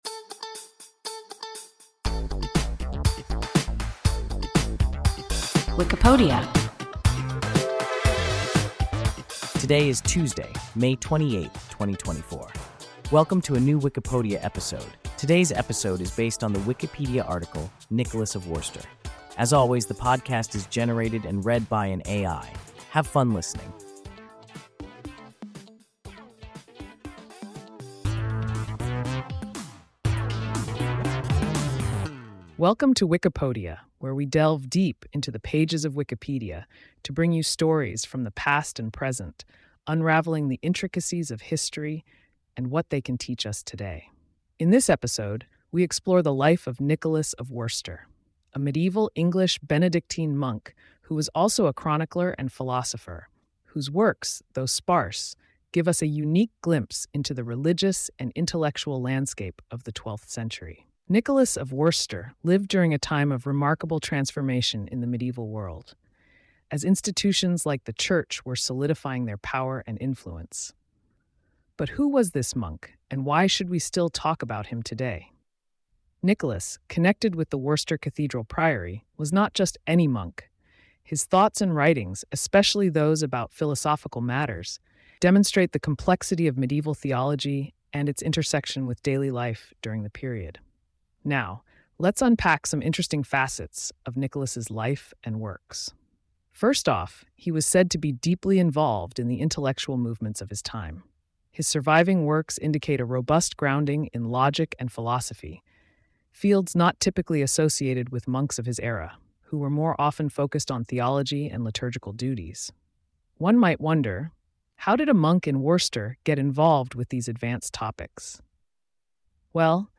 Nicholas of Worcester – WIKIPODIA – ein KI Podcast